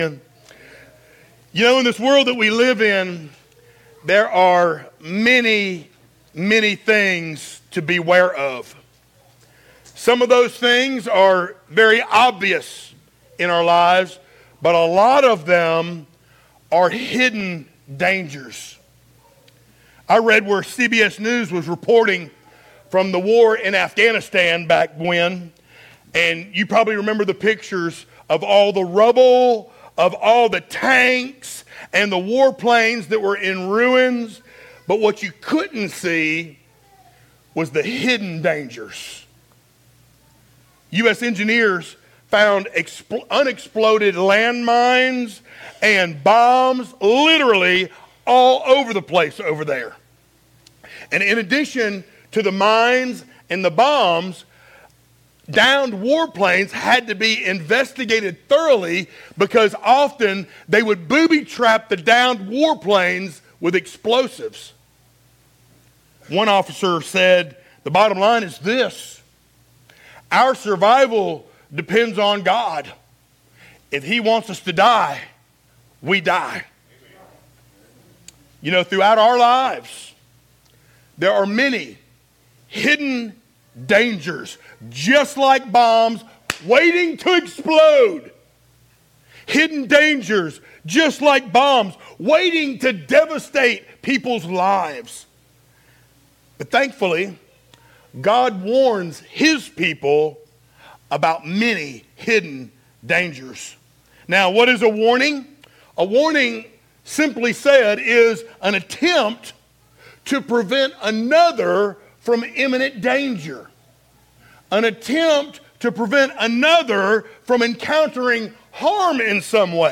sermons Passage: Psalm 19:7-11 Service Type: Sunday Morning Download Files Notes « EASTER “Living in the Present